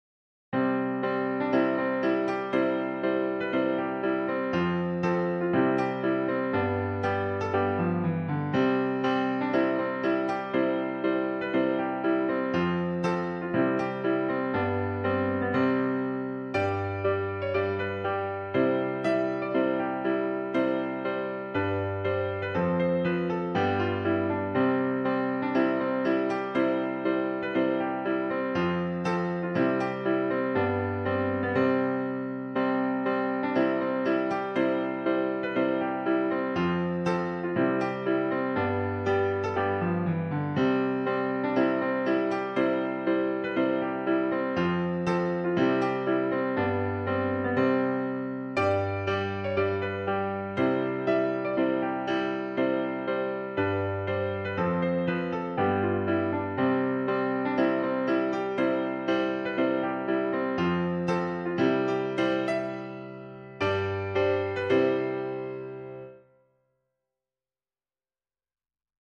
Patriotic Song
piano solo